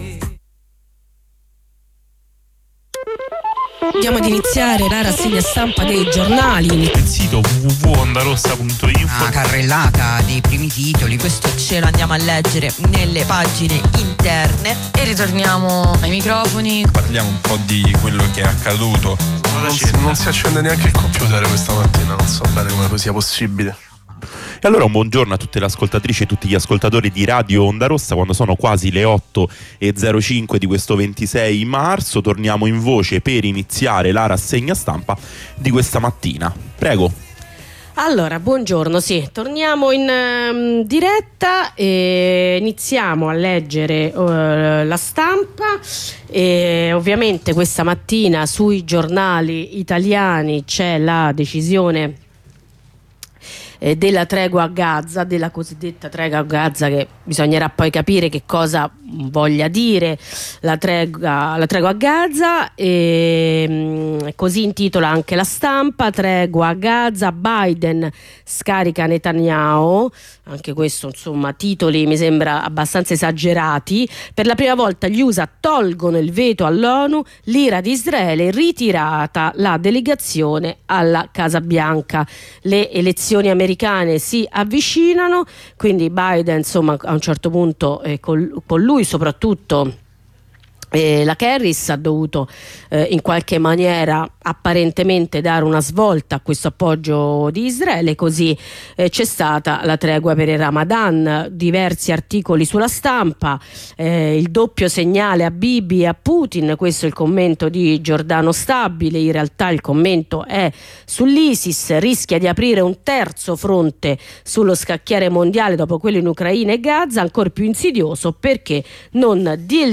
Lettura e commento dei quotidiani di oggi.